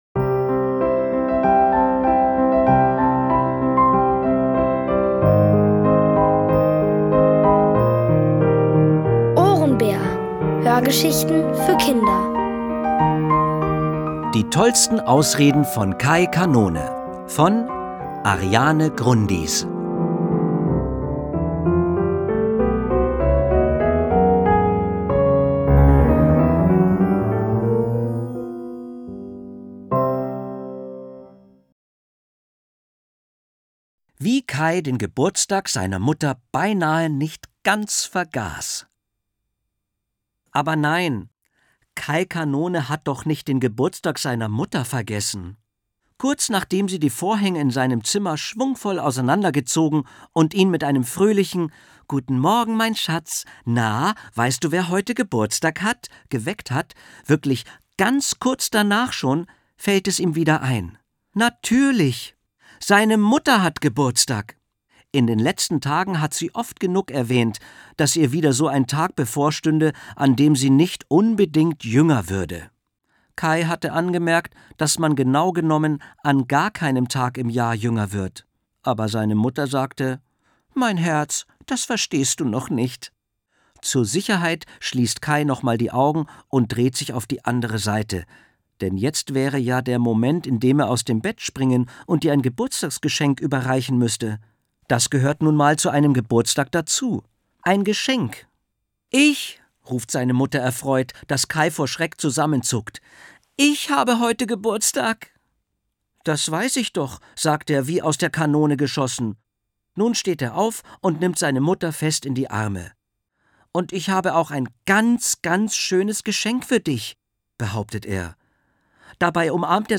OHRENBÄR – das sind täglich Hörgeschichten für Kinder zwischen 4 und 8 Jahren. Von Autoren extra für die Reihe geschrieben und von bekannten Schauspielern gelesen.